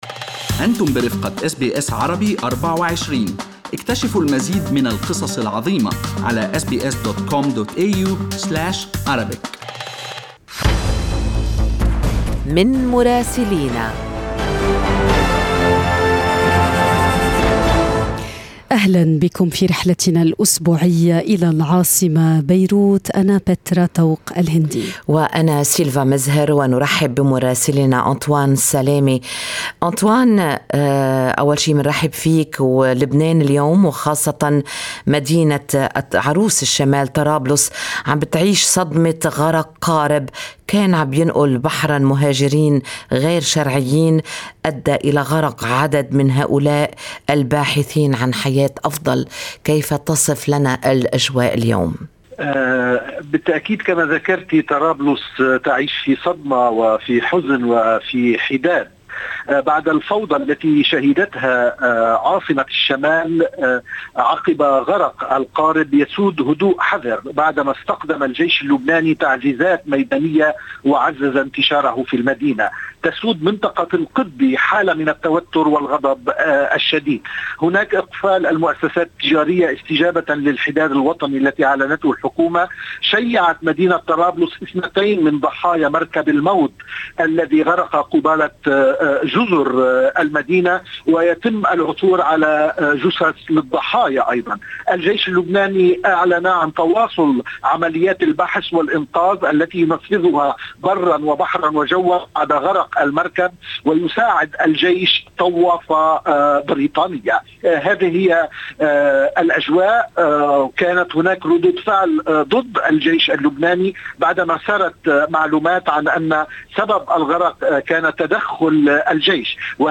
من مراسلينا: أخبار لبنان في أسبوع 26/4/2022